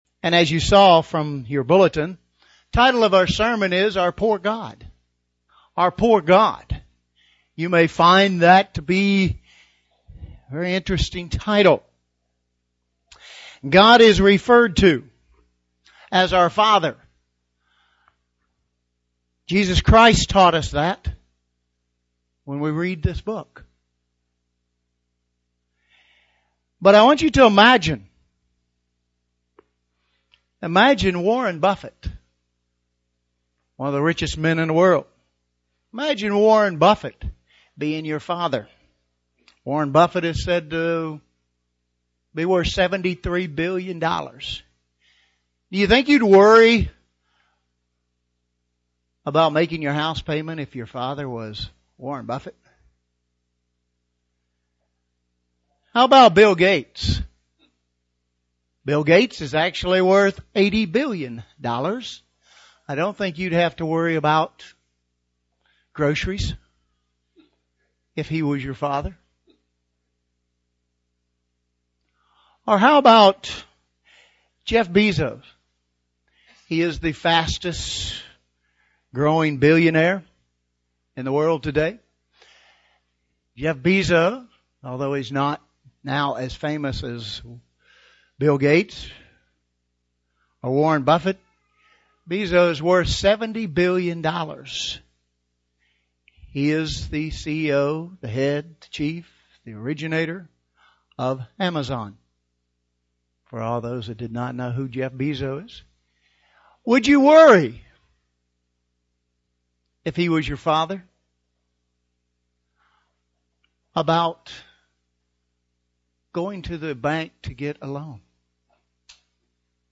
Is God so poor that He needs your money? sermon Transcript This transcript was generated by AI and may contain errors.